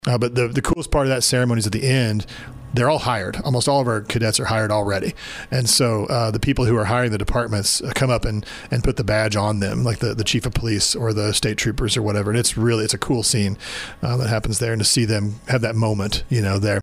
Park Hills, Mo. (KFMO) - The Mineral Area College Law Enforcement Academy recognized the graduates of Class 25-112 during a ceremony held this week at the Cardinal Center.